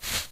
clothes1.ogg